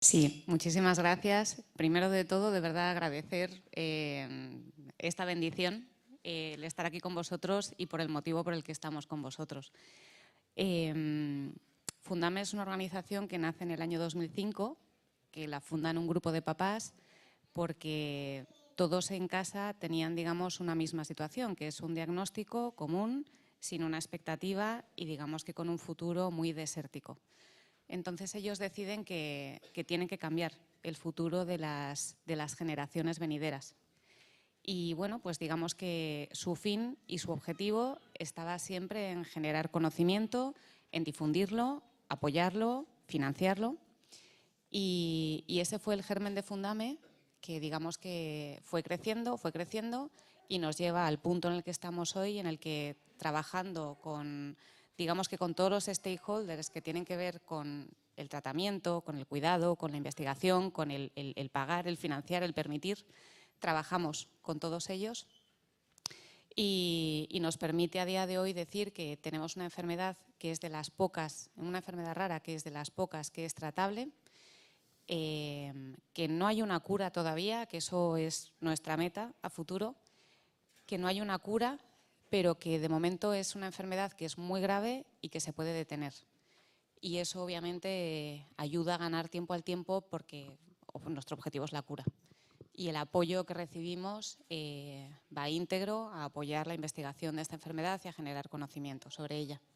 El acto de entrega de los cheques solidarios del programa ‘Gracias A Ti’ reunió a trabajadores de todas las áreas ejecutivas del Grupo Social ONCE (ONCE, Fundación ONCE e ILUNION) en un acto celebrado en el Palacete de los Duques de Pastrana en Madrid el pasado 10 de febrero, que también pudo seguirse en streaming.